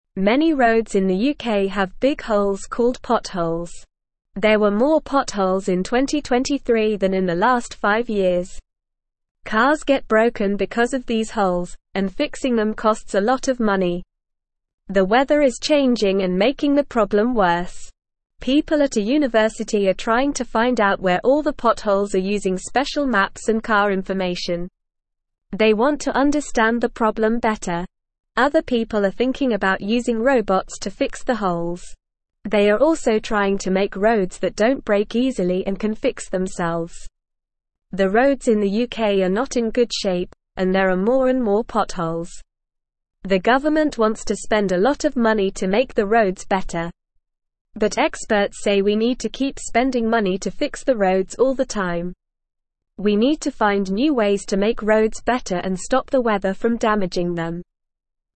Normal
English-Newsroom-Lower-Intermediate-NORMAL-Reading-UK-Roads-with-Holes-Smart-People-Fixing-Them.mp3